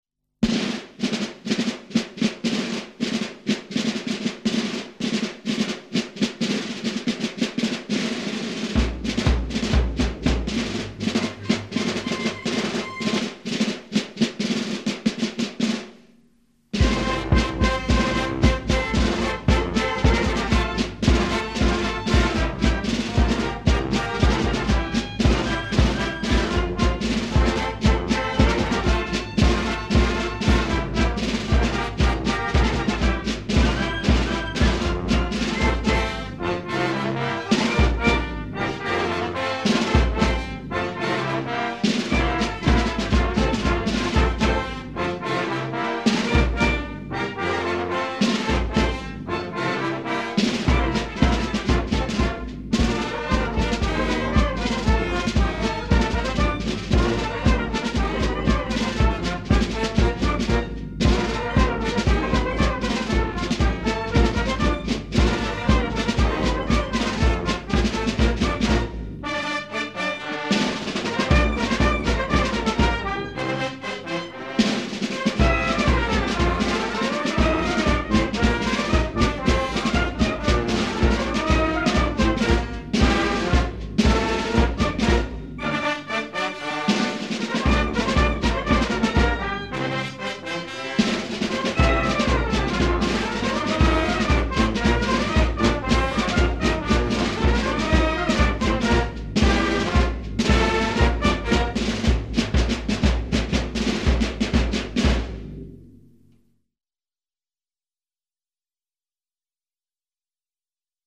polka marche.mp3